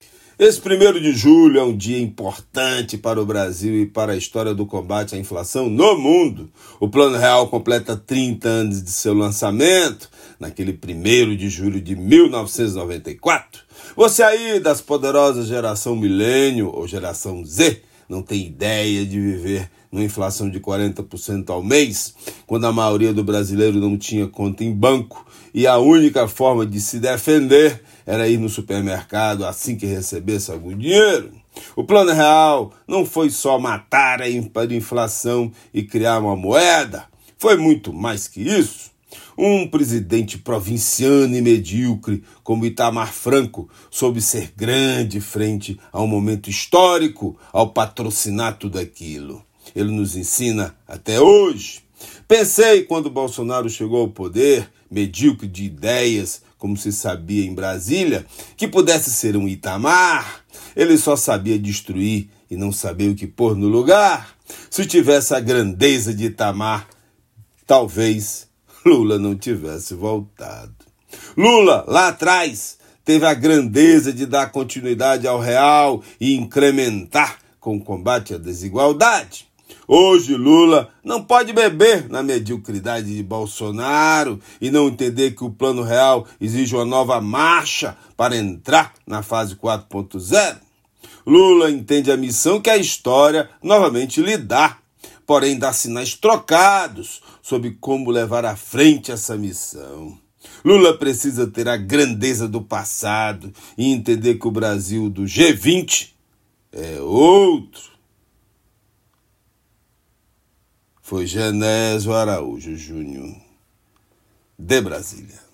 Comentário desta segunda-feira
direto de Brasília.